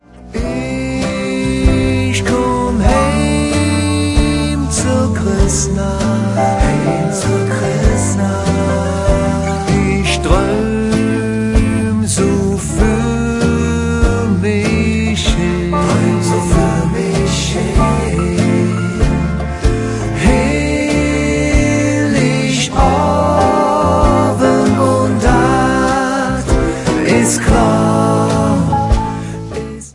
Advents- und Weihnachtsleedcher in Kölner Mundart